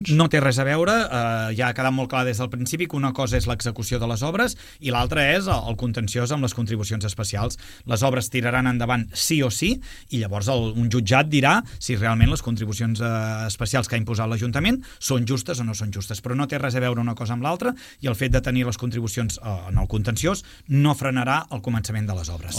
Segons assegura Puig a Ràdio Palamós, les reclamacions no afectaran al calendari de les obres i serà un jutge qui decideixi si les contribucions són justes o no.